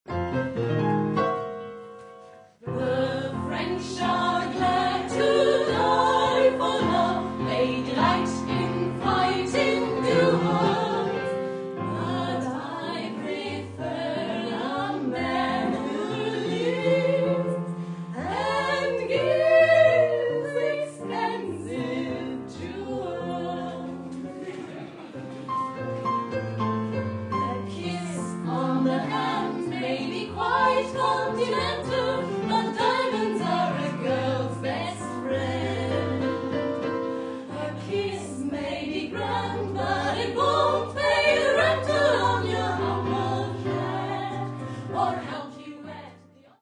Junge Menschen singen von einer Zeit, von der sie keine Ahnung haben